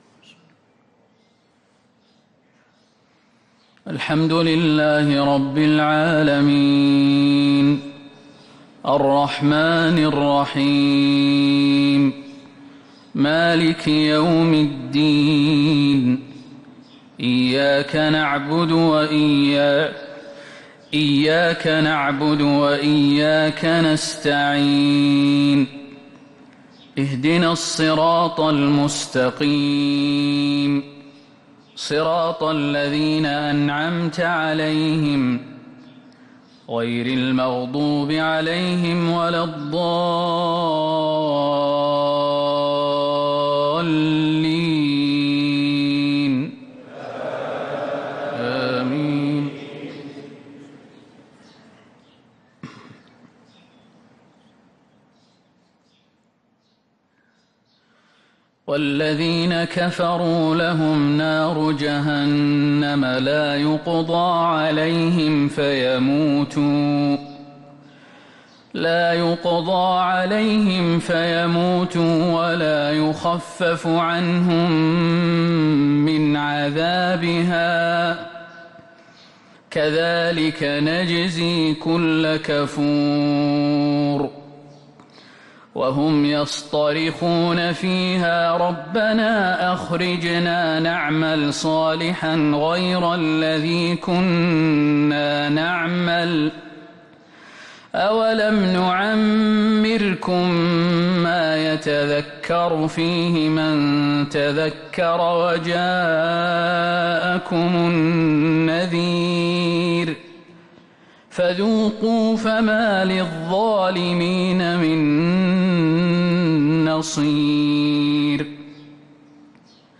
صلاة الفجر للقارئ خالد المهنا 24 صفر 1445 هـ